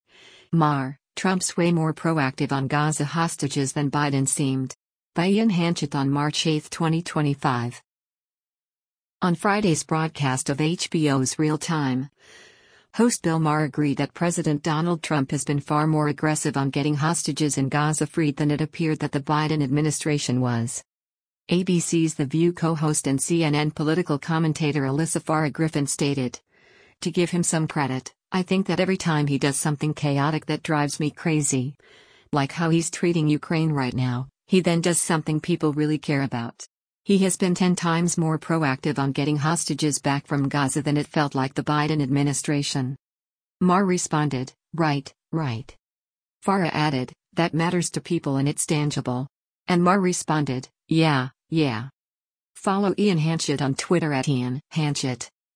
On Friday’s broadcast of HBO’s “Real Time,” host Bill Maher agreed that President Donald Trump has been far more aggressive on getting hostages in Gaza freed than it appeared that the Biden administration was.